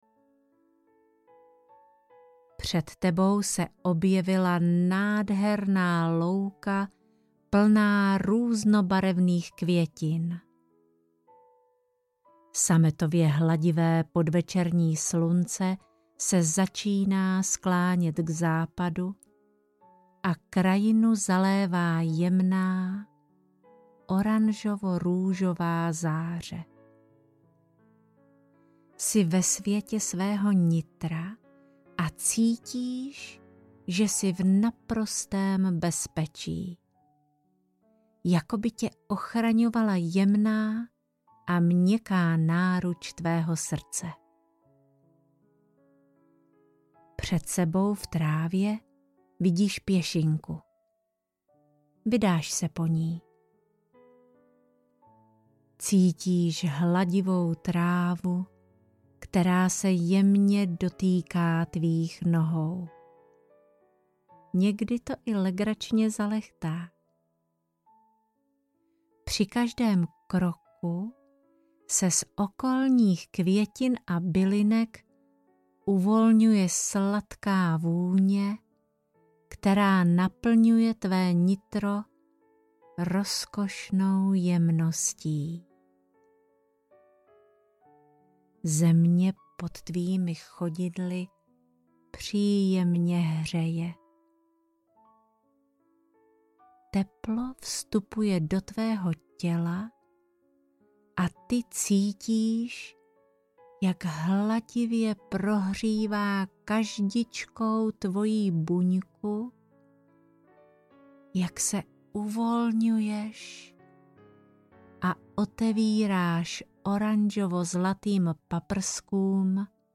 Meruňková babička audiokniha
Ukázka z knihy
merunkova-babicka-audiokniha